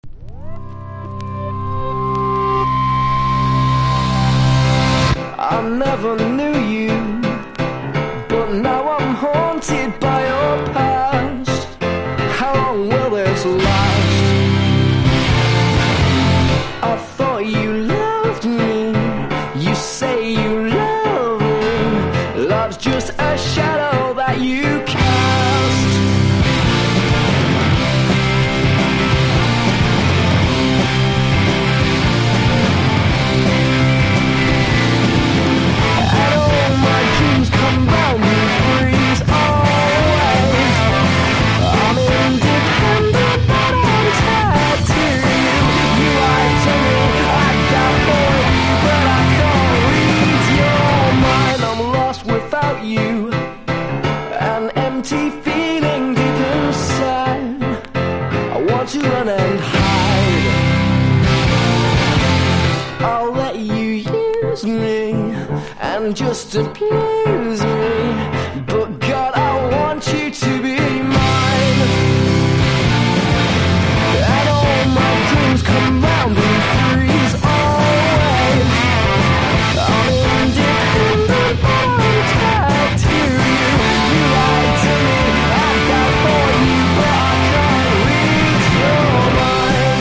NEO ACOUSTIC / GUITAR POP
両面共に泣きメロ&キャッチーな人気曲。